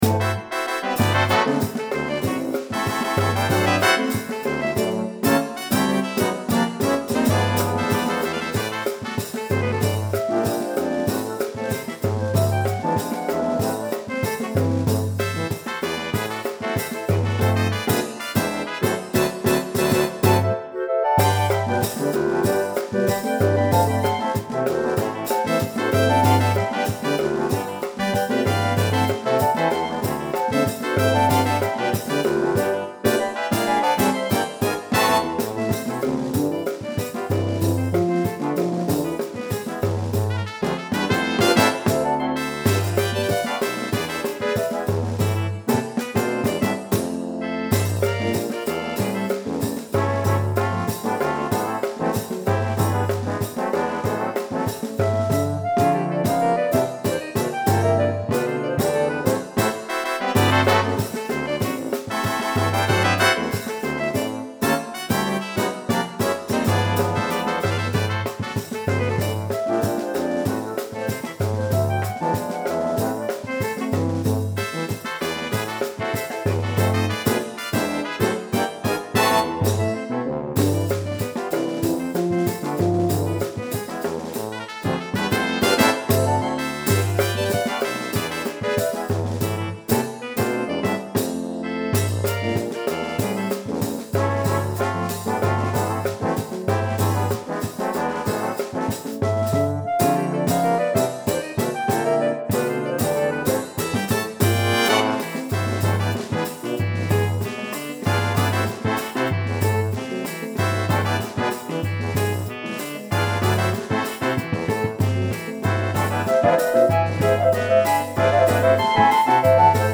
a un rico y variado repertorio de música de banda